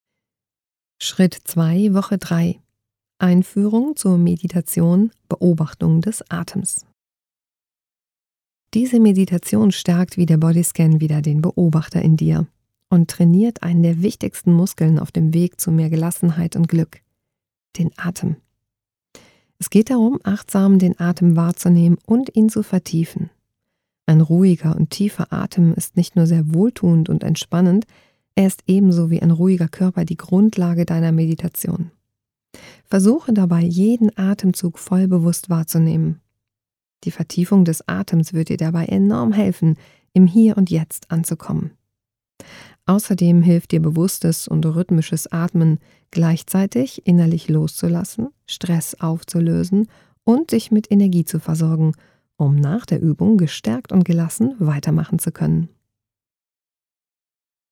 Meditations CD oder MP3 zum Buch. Starte mit 8-Wochen geführten Meditationen!